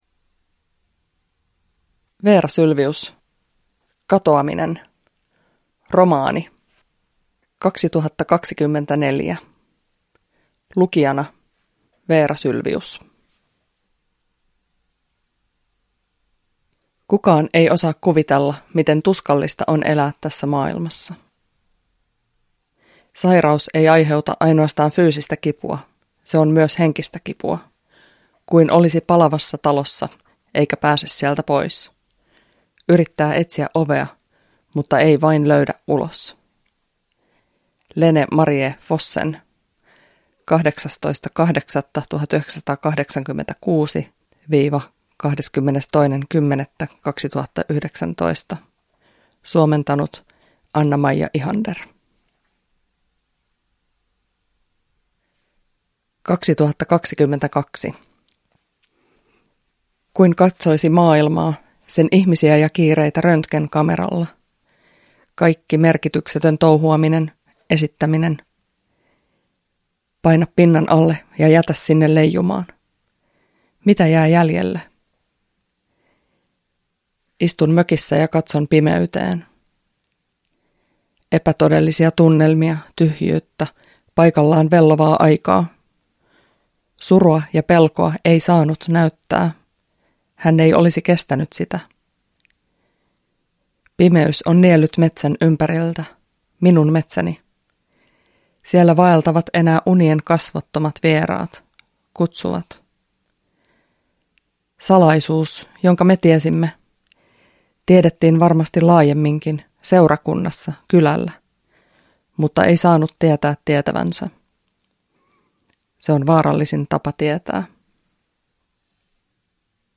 Katoaminen – Ljudbok